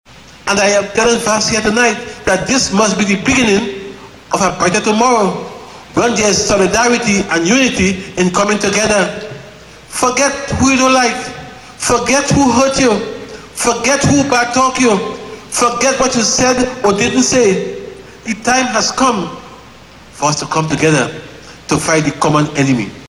At a thanksgiving event in the Chaguanas west constituency yesterday, Mr. Warner expressed the need to fight a common enemy, he did not name.
Former Prime Minister Panday was among those in the audience.